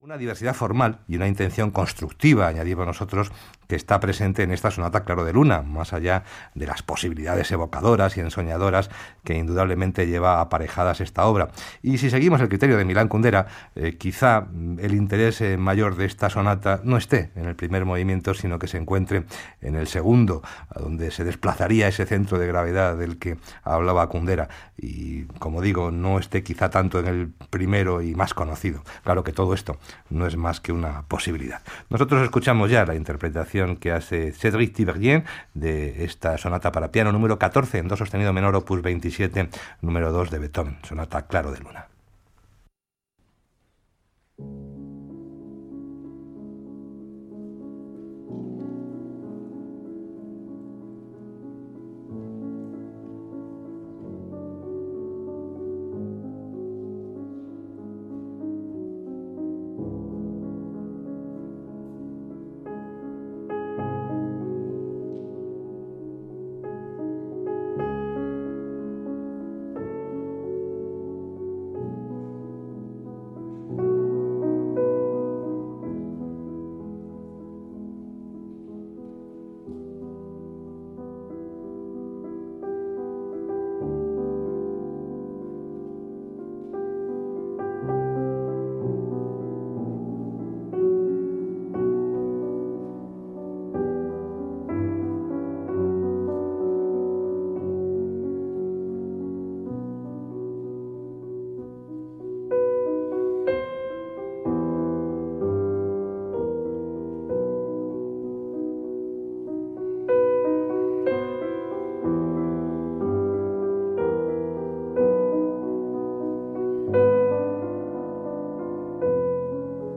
Cédric Tiberghien In Recital - Music of Beethoven, Ravel, Schumann and Debussy - 2011 - Past Daily Mid-Week Concert - Recitals.
Cédric Tiberghien – in recital – Recorded May 21, 2011 – ORF-Austria –
French pianist Cédric Tiberghien in recital this week with a fairly familiar repertoire of the tried-and-true. Beginning the concert with a pereformance of the Beethoven Piano Sonat #14 (Moonlight) and then a performance of Schumann’s Kreisleriana, Ravel’s Gaspard de la nuit and finishing with Debussy’s Masques d’un Chaier d’esquisses and L’isle Joyeuse.